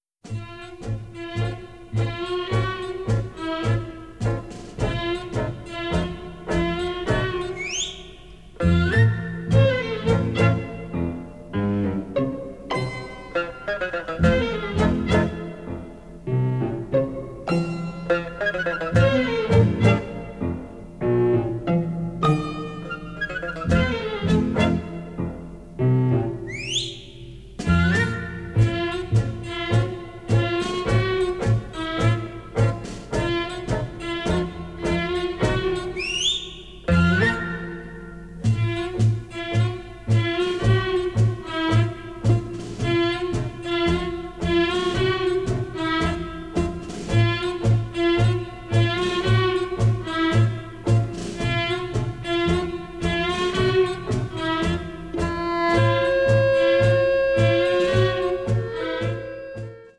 including the tango and Charleston